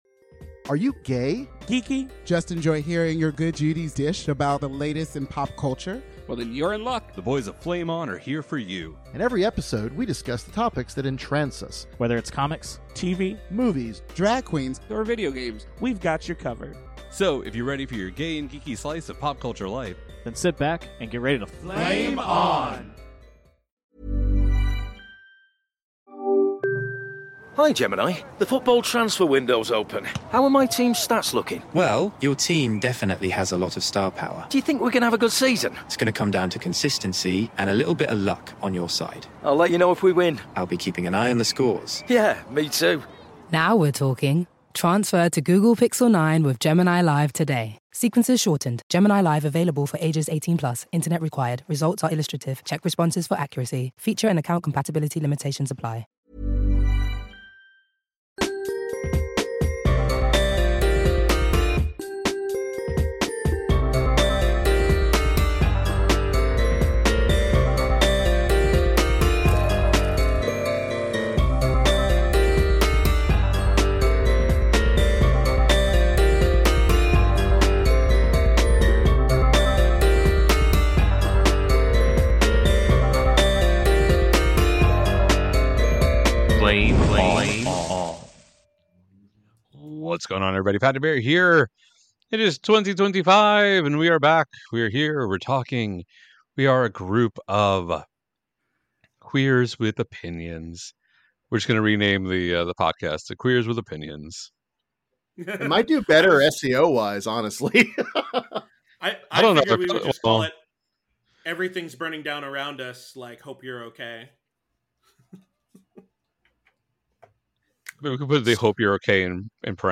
Rather than a more structured conversation, the boys will chat about the topics that are tickling their fancy while entertaining any tangents they find themselves on. From Drag Race Season 17 to Squid Game Season 2, the boys are ready to kick off the year with a bang!